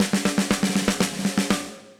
Index of /musicradar/80s-heat-samples/120bpm
AM_MiliSnareB_120-01.wav